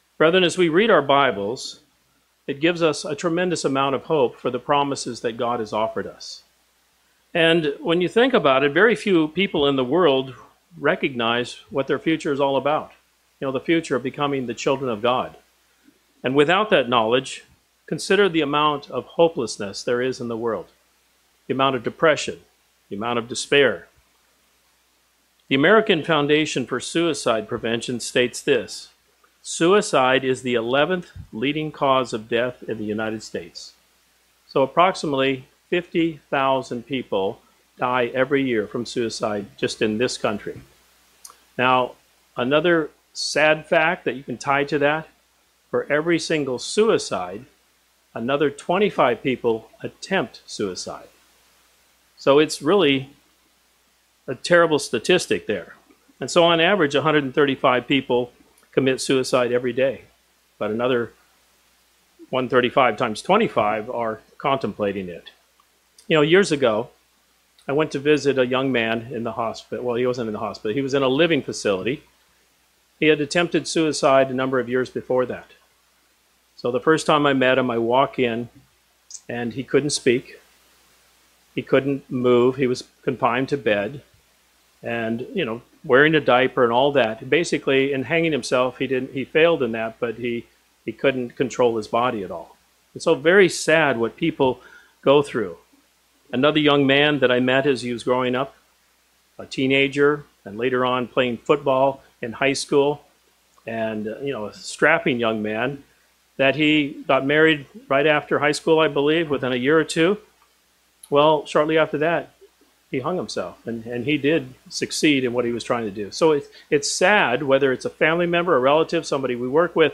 When trials come our way, our hope provides a solid foundation for remaining confident in the wonderful promises of God. This sermon offers four practical ways in which the scriptures remind us to abound in hope.